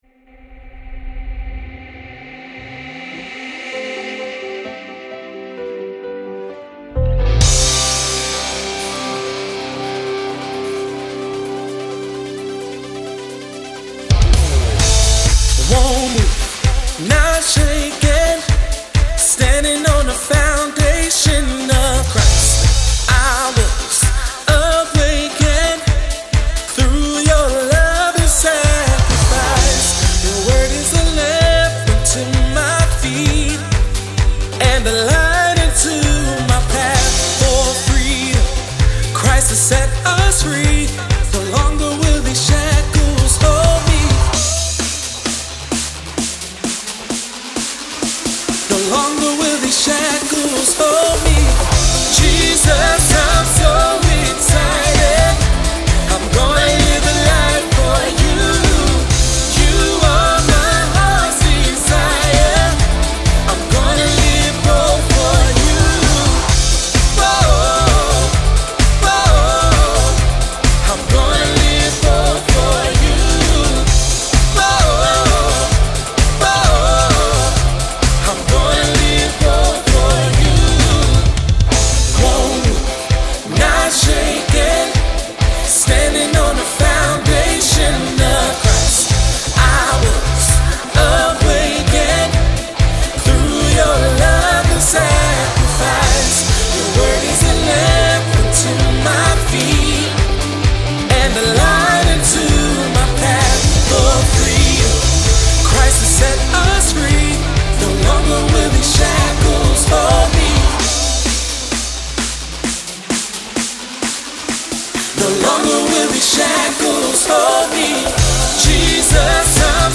upbeat, high energy track